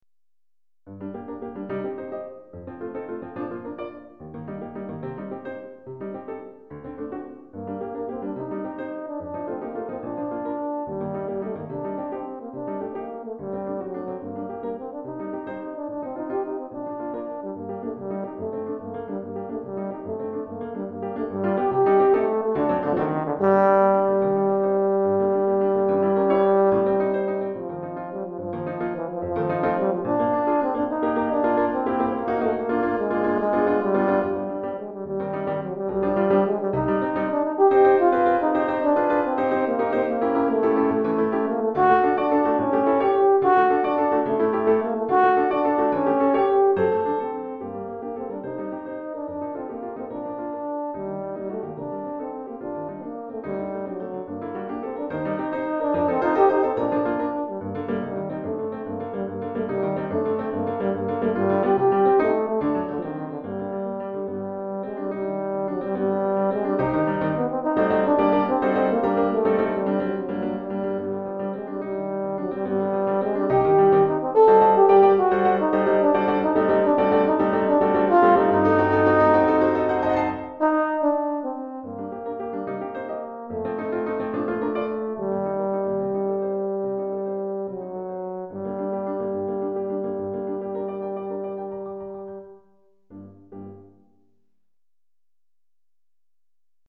Cor et Piano